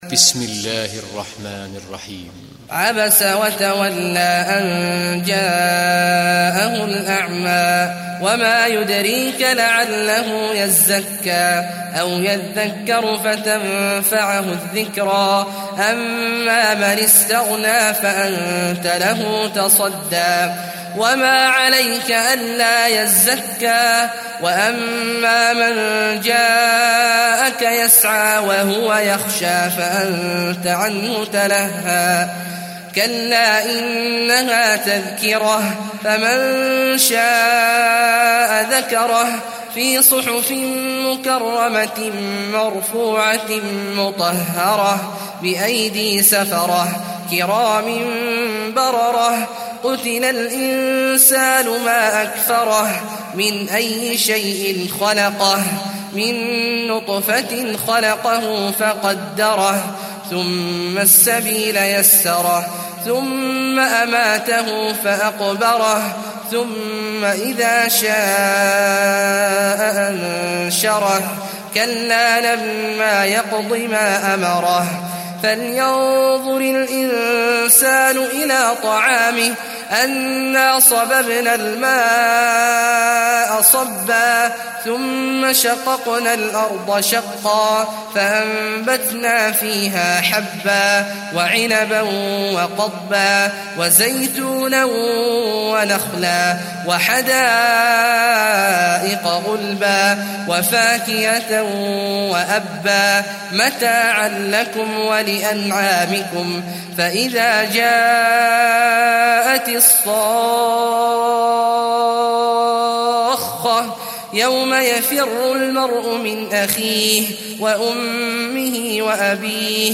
Abese Suresi mp3 İndir Abdullah Awad Al Juhani (Riwayat Hafs)